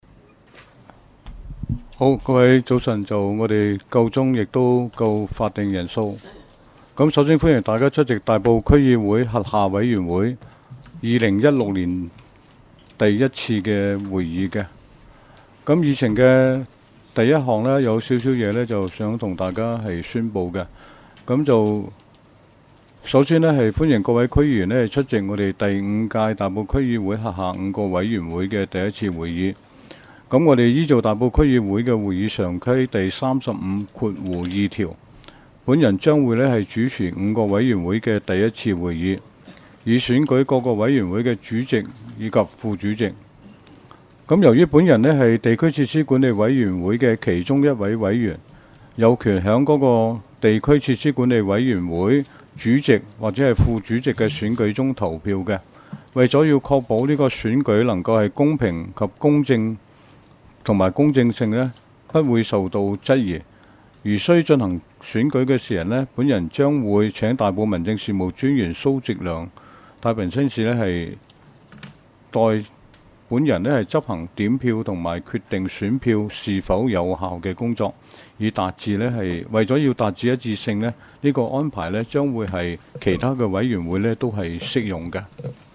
委员会会议的录音记录
全部展开 全部收回 议程:I 大埔区议会主席致欢迎辞 讨论时间: 0:01:25 前一页 返回页首 议程:II 选举大埔区议会辖下委员会主席及副主席，次序如下： 1.